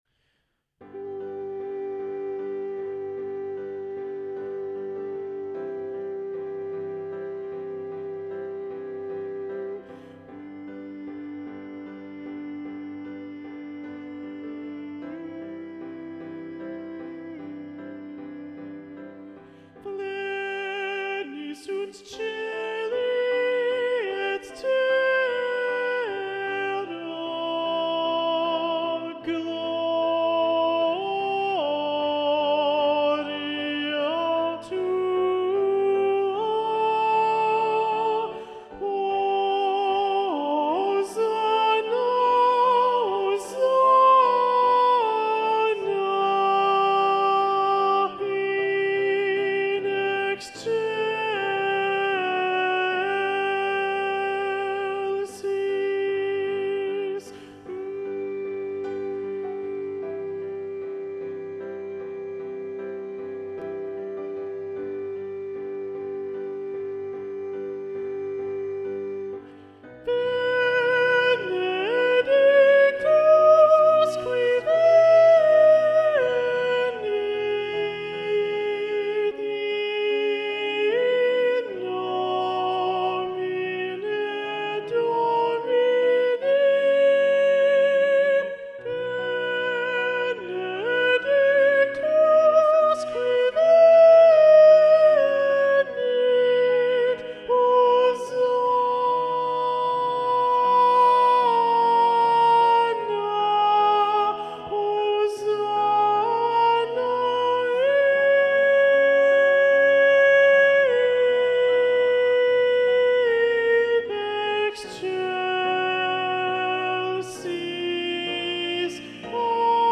Soprano 1
The-Ground-Soprano-1-Predominant-Ola-Gjeilo.mp3